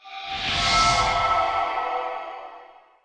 SFX法术空灵空洞音效下载
SFX音效